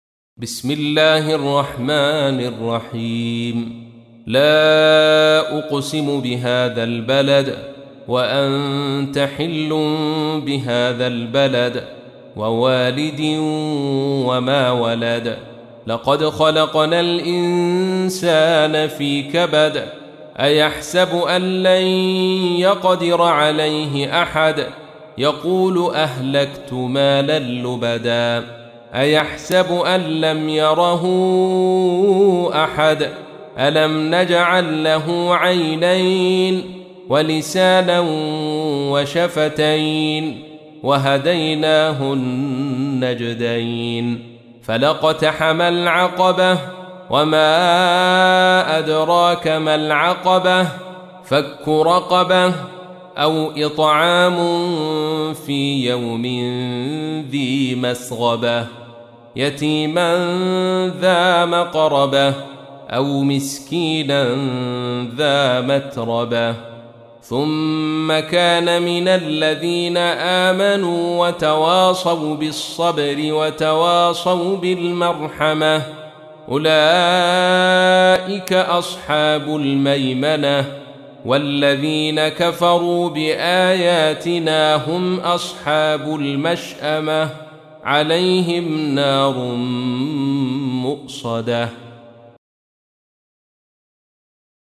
تحميل : 90. سورة البلد / القارئ عبد الرشيد صوفي / القرآن الكريم / موقع يا حسين